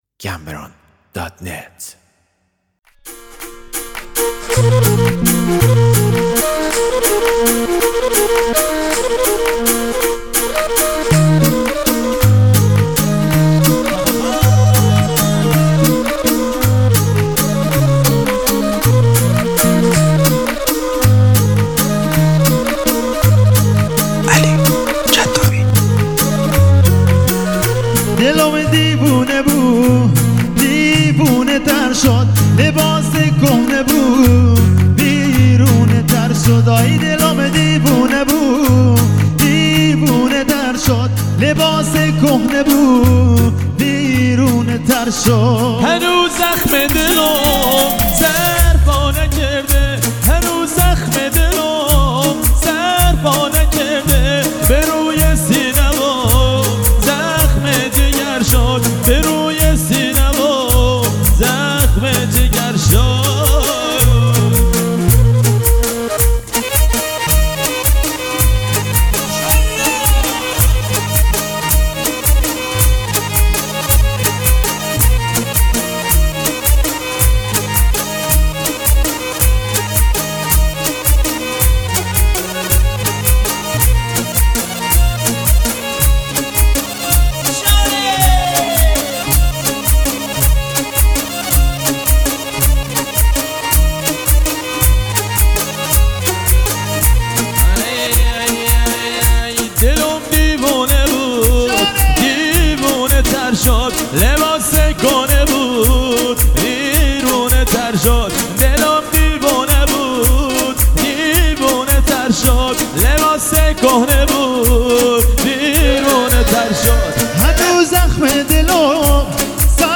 دانلود آهنگ بستکی
آهنگ بلوچی